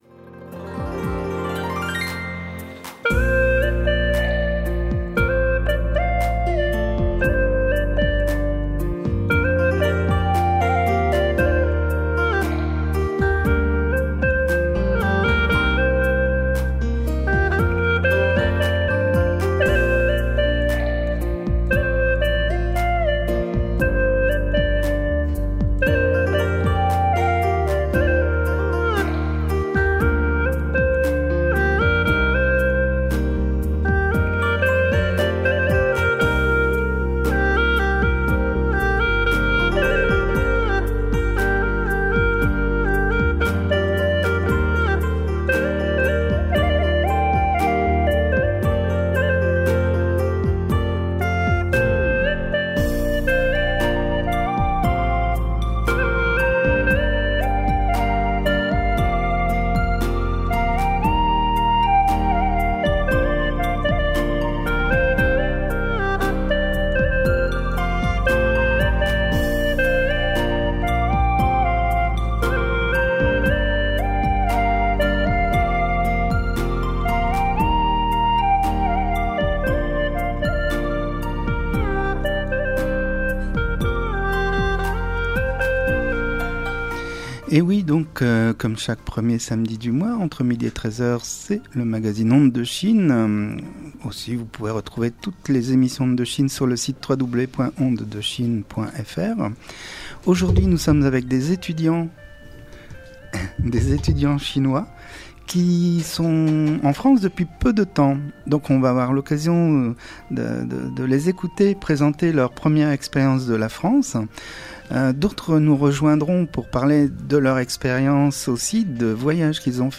l'atelier radio des �tudiants chinois de Lille